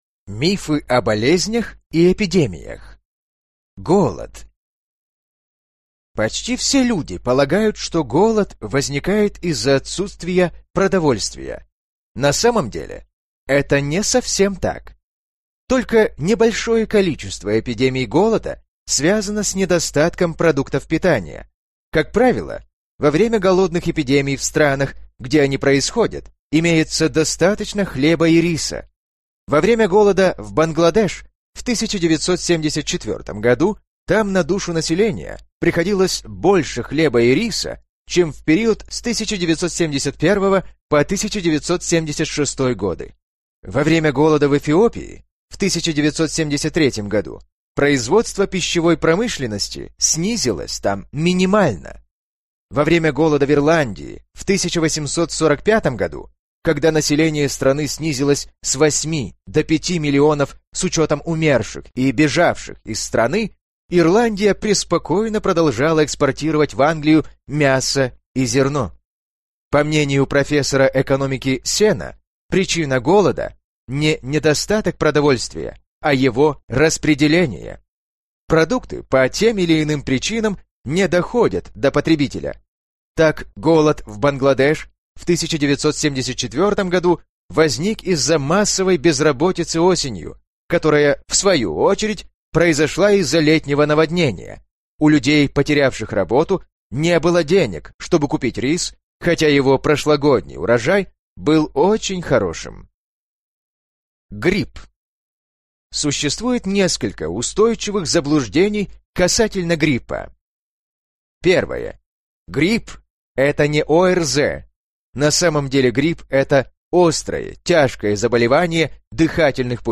Аудиокнига Великая книга заблуждений | Библиотека аудиокниг